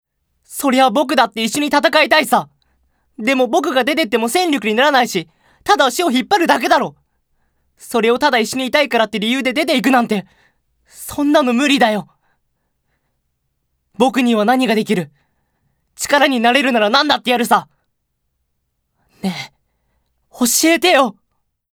高音出ます。
ボイスサンプル、その他
セリフ１